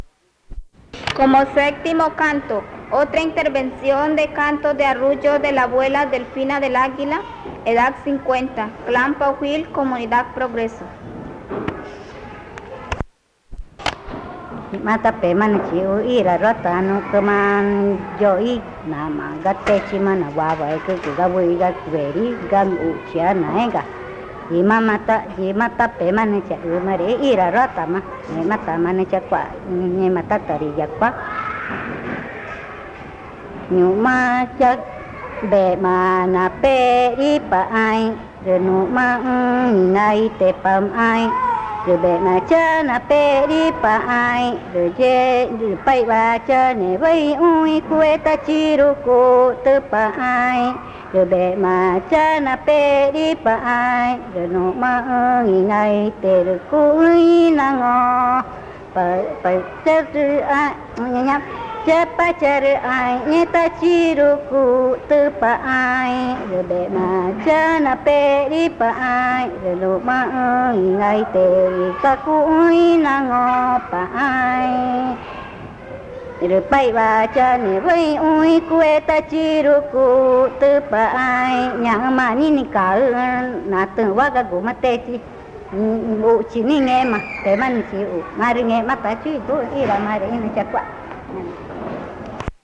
Nazareth, Amazonas (Colombia)